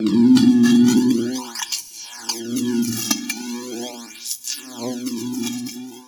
buzz.ogg